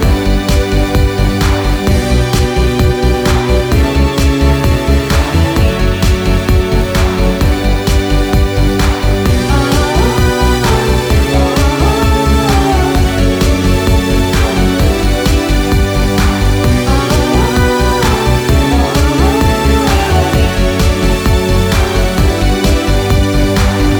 no Backing Vocals R'n'B / Hip Hop 3:45 Buy £1.50